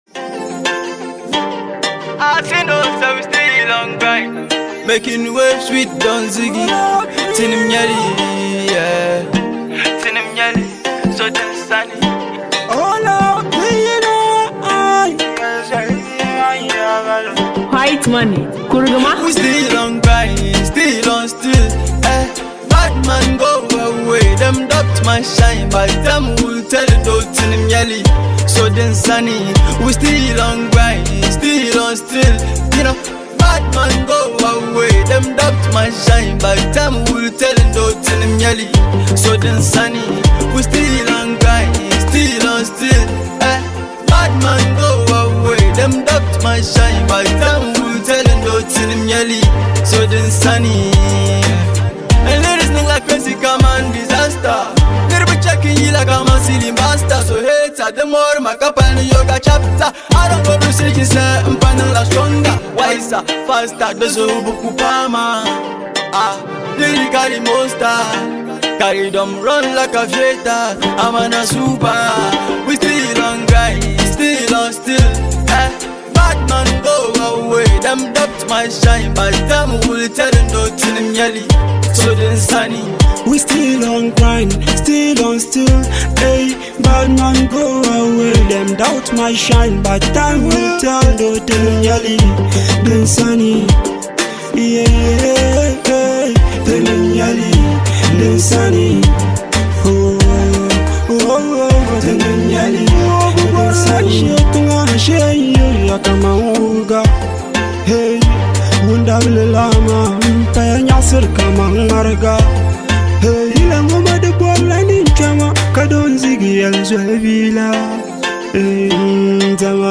With its confident vibe and strong chemistry